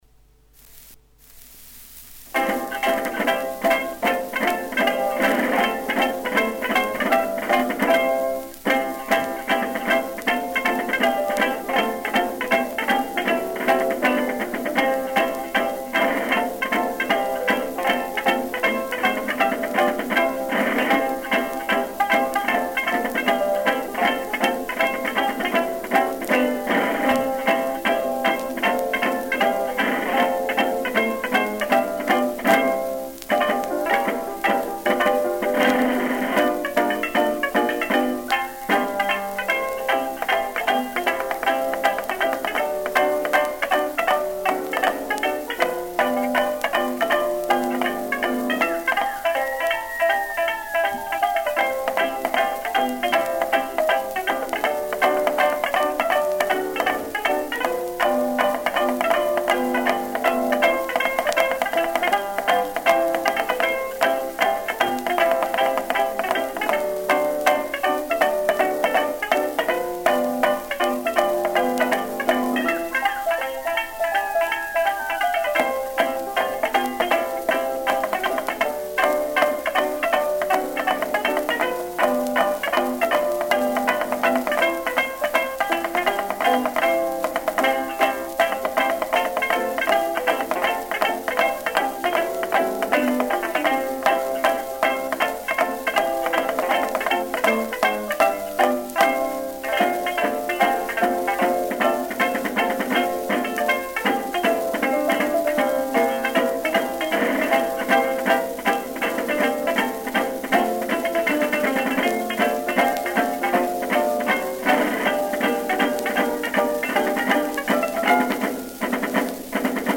Recorded in New York City, January 27, 1915.
Banjo
Drums
Piano